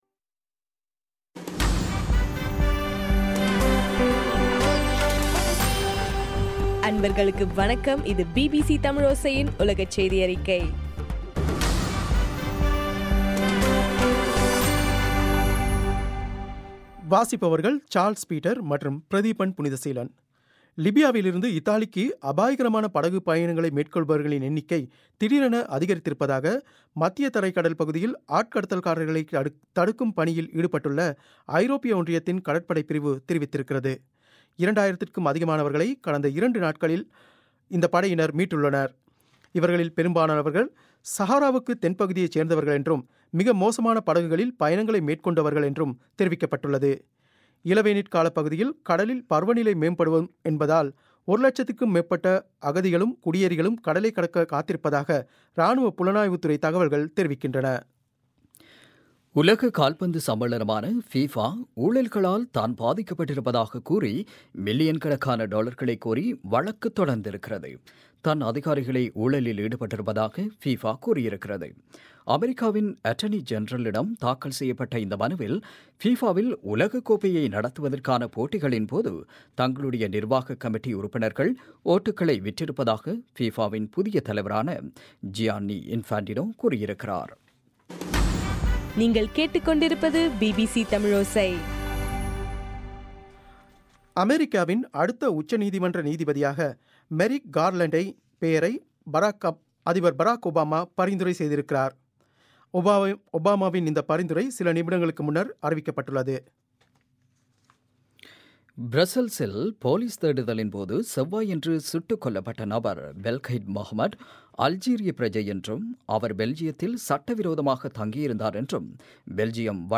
பிபிசி தமிழோசை- உலகச் செய்தியறிக்கை- மார்ச் 16